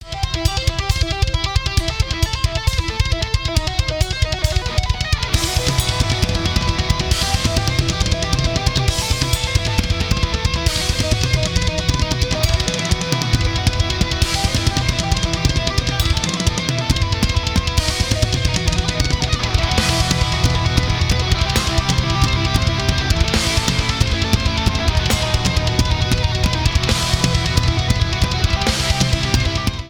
• Rock
heavy metal band